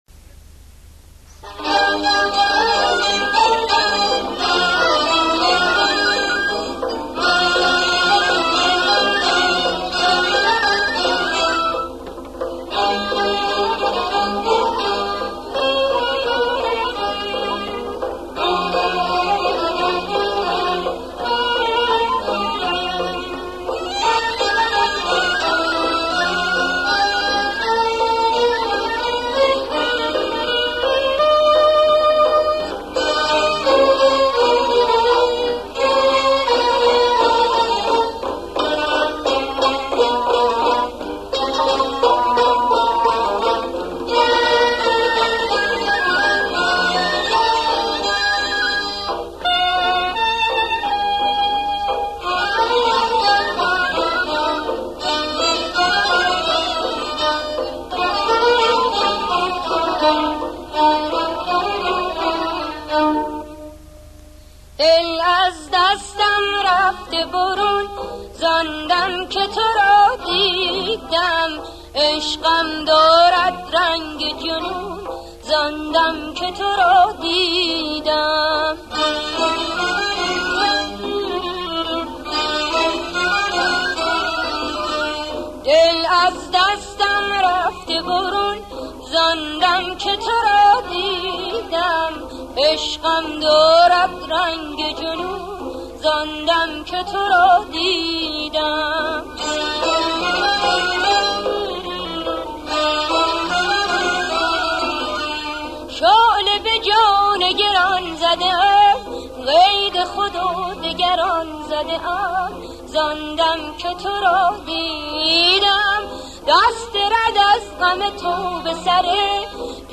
دستگاه: اصفهان